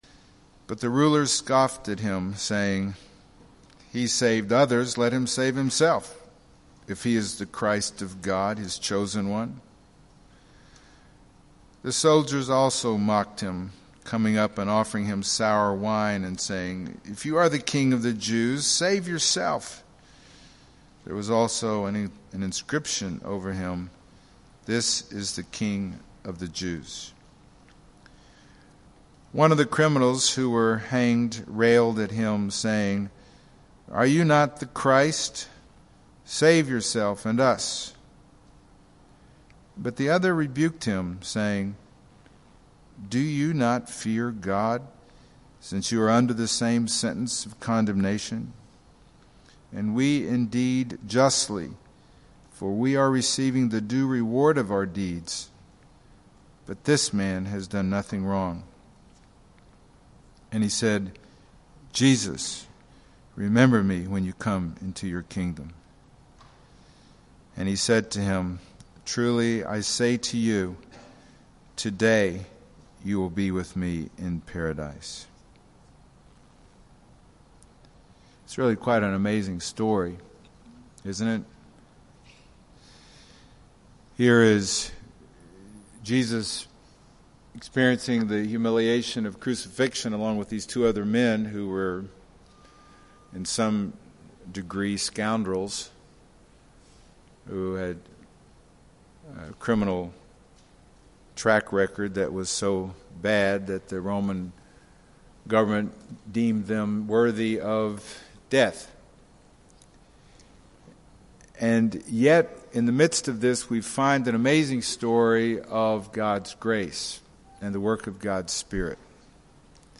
Passage: Luke 23:36-43 Service Type: Sunday Morning